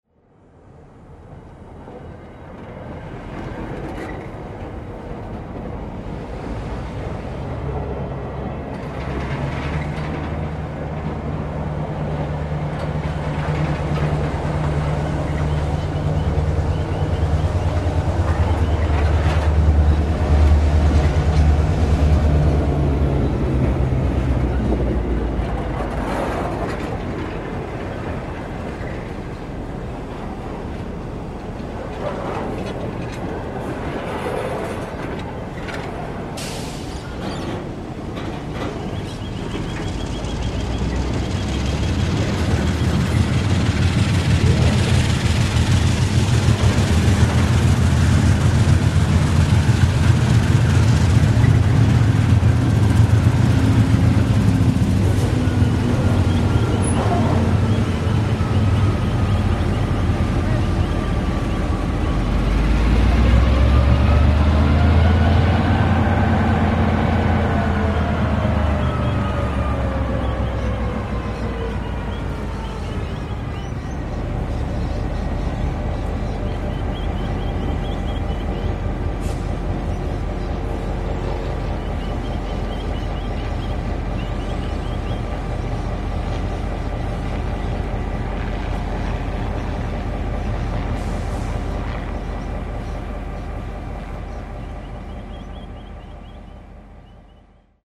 Note I have faded these in and out just to go easy on your ears.
5 - Diesel Shunting 2 - 11 min 45 secs
Modern shunting yard sounds abound in this multi-track clip, based on several days of recordings near Acacia Ridge yard and featuring NRs, 422's, ELs, 2100s, 2400s and 2800s.  Once again, there are no horn sounds, so can be used to represent a range of modern era localities.
Dieselshunting2_web.mp3